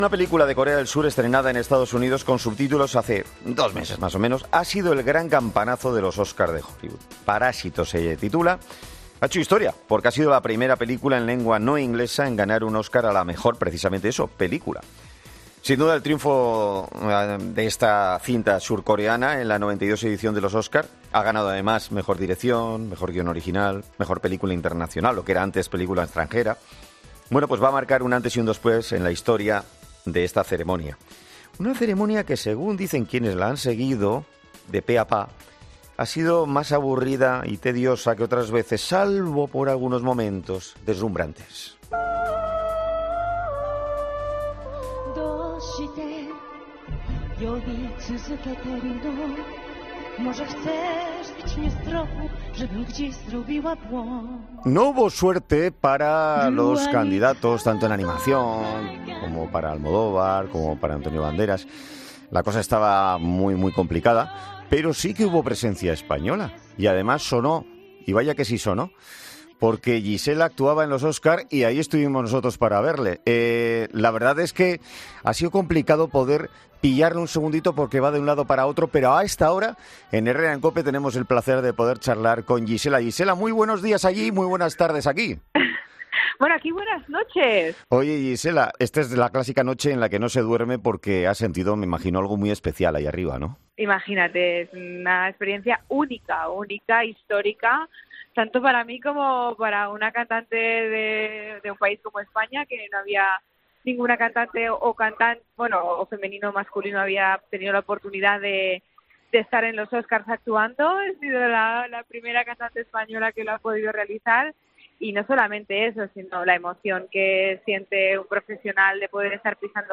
Tras ello, este lunes ha sido entrevistada en 'Herrera en COPE' la propia Gisela, que ha dicho que ha sido una "experiencia única" porque ha sido la primera mujer española en subirse al escenario y cantar en los Oscar.